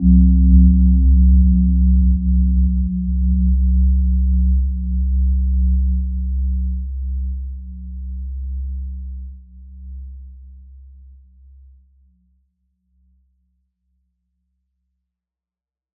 Gentle-Metallic-4-C2-p.wav